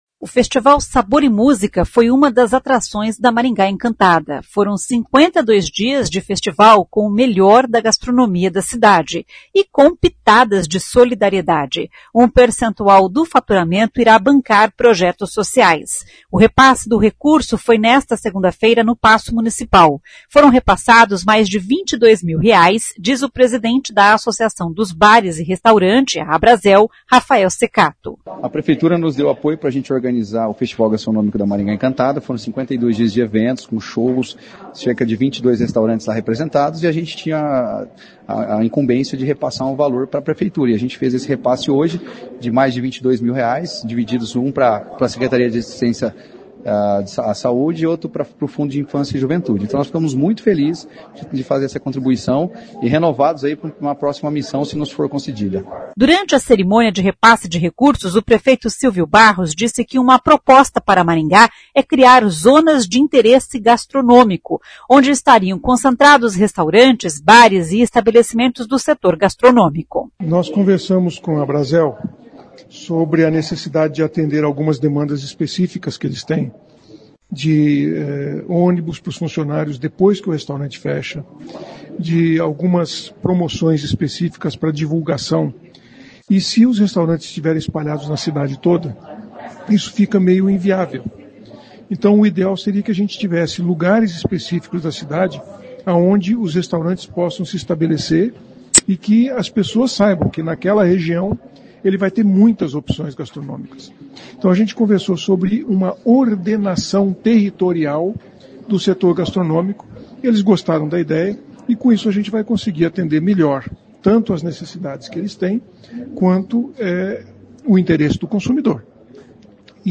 Durante a cerimônia de repasse de recursos, o prefeito Silvio Barros disse que uma proposta para Maringá é criar zonas de interesse gastronômico, onde estariam concentrados restaurantes, bares e estabelecimentos do setor gastronômico.